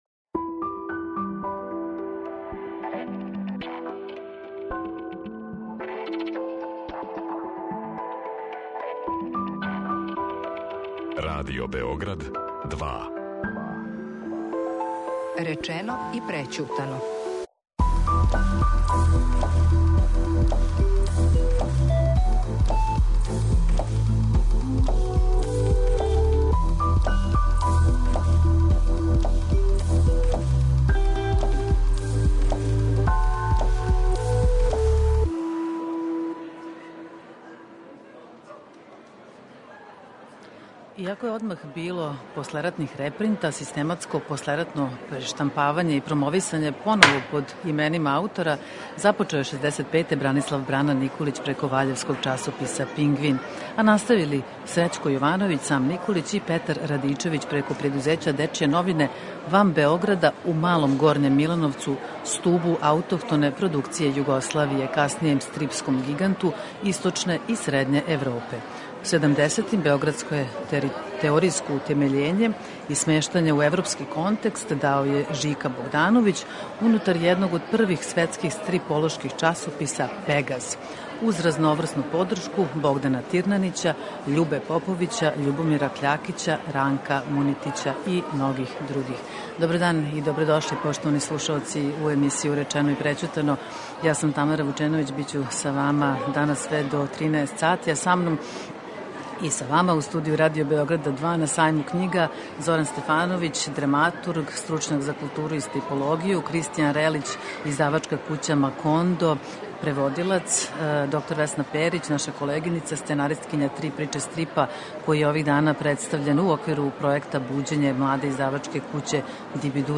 Гости у студију Радио Београда 2 на Сајму књига